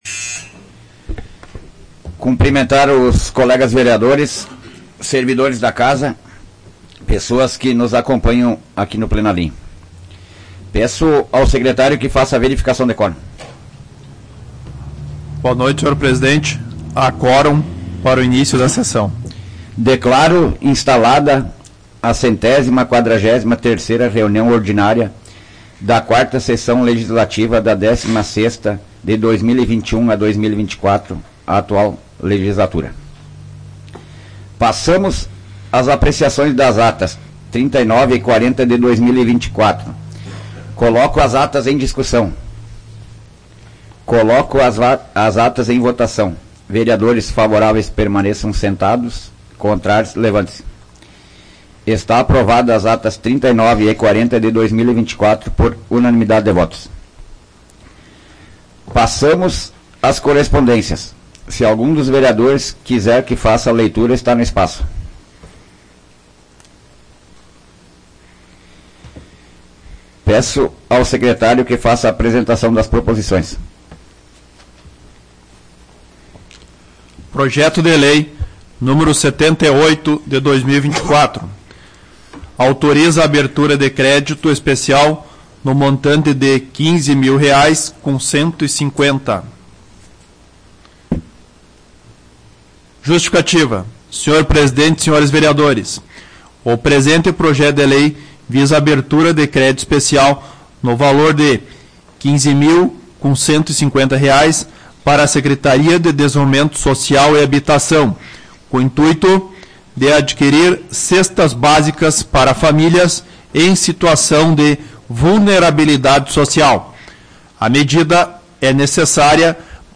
Áudio da 143ª Sessão Plenária Ordinária da 16ª Legislatura, de 5 de agosto de 2024